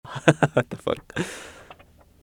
Laughing 2 Sound Button - Free Download & Play